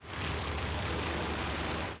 Minecraft Version Minecraft Version snapshot Latest Release | Latest Snapshot snapshot / assets / minecraft / sounds / mob / guardian / attack_loop.ogg Compare With Compare With Latest Release | Latest Snapshot
attack_loop.ogg